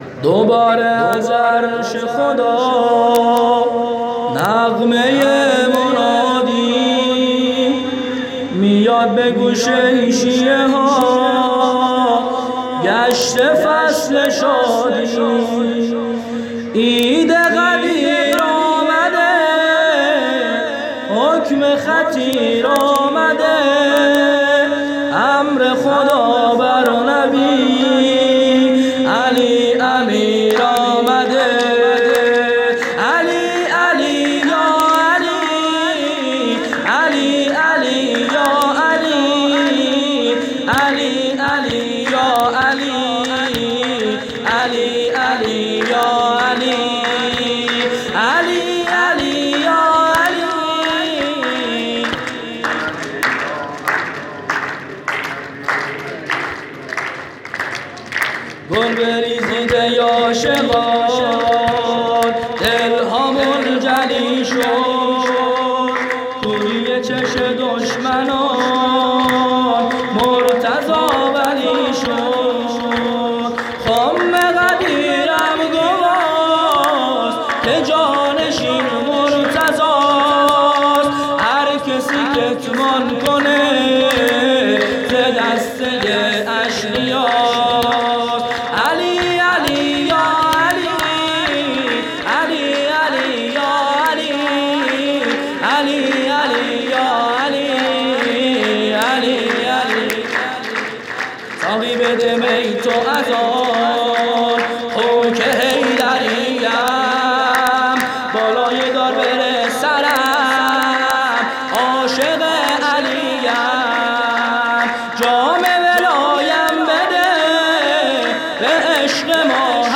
گزارش صوتی عید غدیر ۹۸